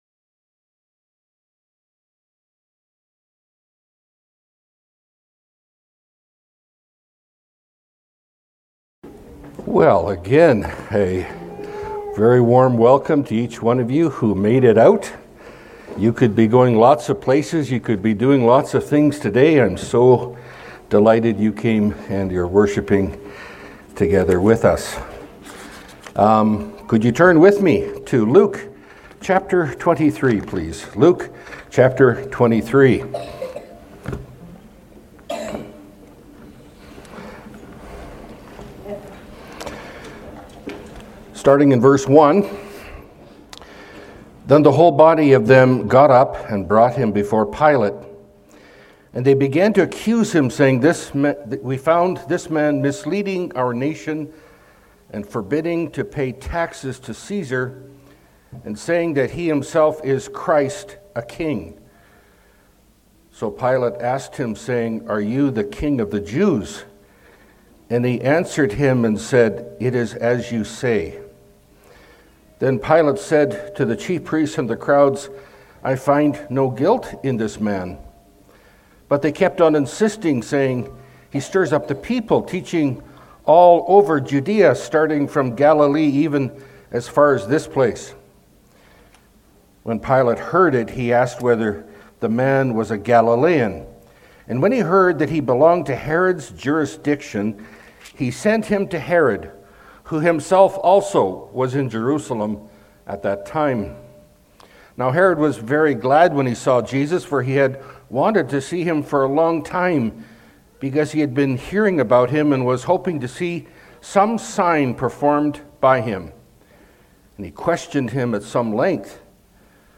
Pulpit Sermons Key Passage